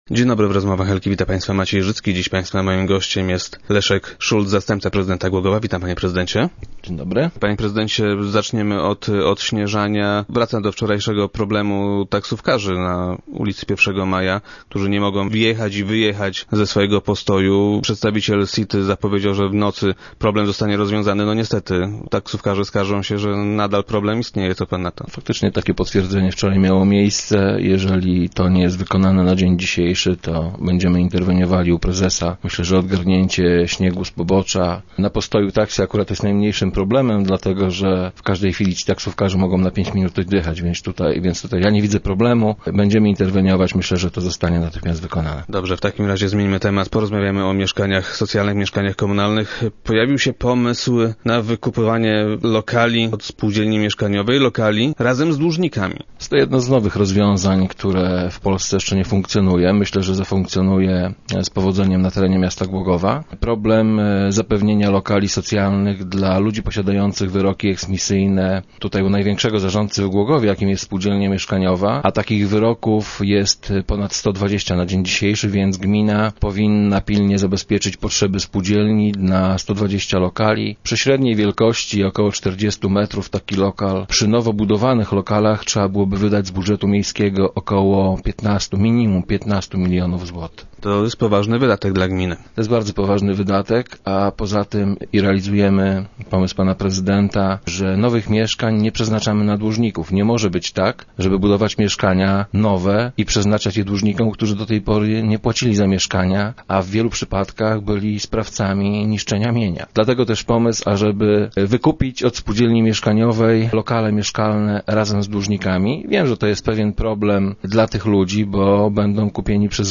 Jak powiedział gość środowych Rozmów Elki, pierwszych trzydzieści mieszkań wraz z dłużnikami, gmina kupi od spółdzielni mieszkaniowej Nadodrze jeszcze w tym roku.